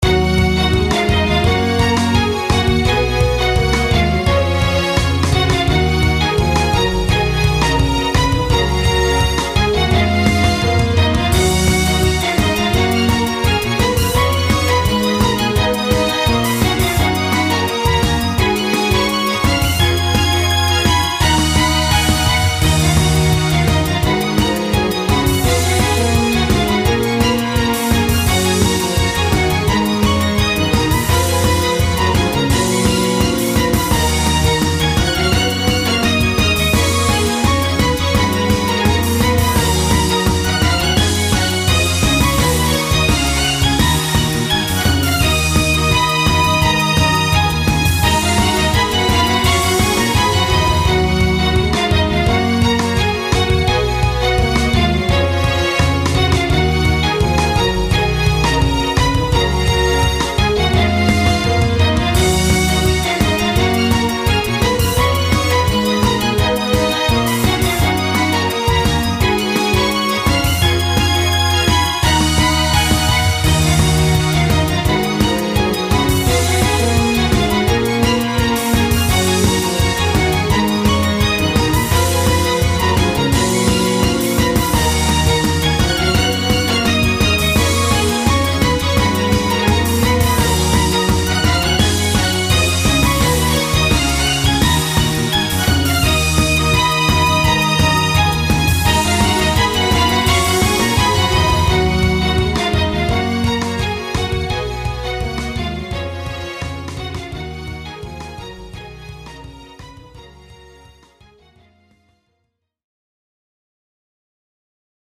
RPGやアクションゲームなどの通常戦闘シーンを想定して制作した、テンポ感のある戦闘用BGMです。
軽快なストリングスのフレーズを中心に構成されており、雑魚戦や小規模バトルといった日常的な戦闘シーンに最適です。
ジャンル： ゲーム音楽／バトルBGM／ファンタジー／アクション
雰囲気： テンポ感／疾走感／緊張感／軽快／前向き